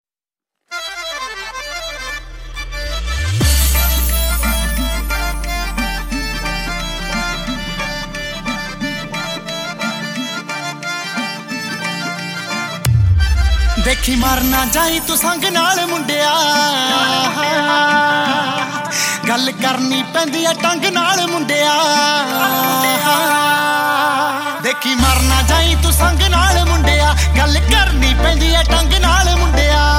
• Качество: 128, Stereo
поп
мужской вокал
зажигательные
dance
bollywood